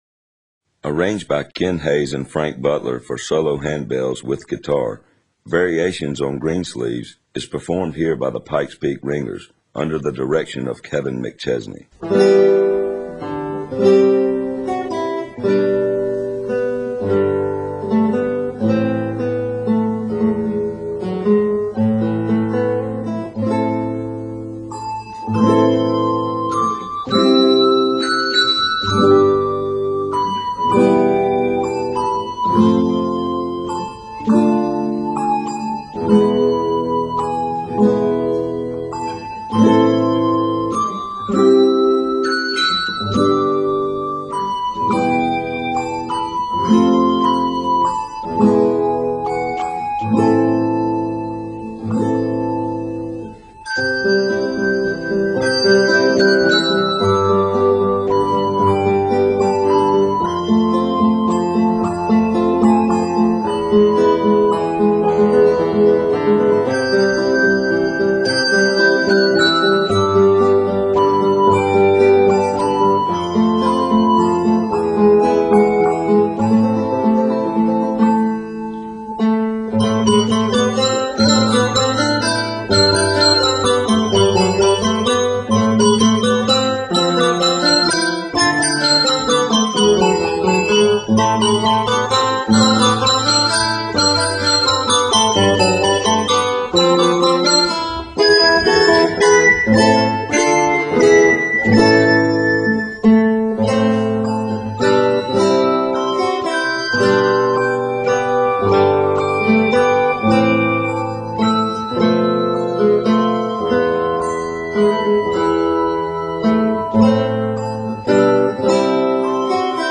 Traditional French Carol